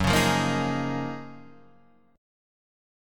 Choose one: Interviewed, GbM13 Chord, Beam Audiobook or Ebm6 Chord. GbM13 Chord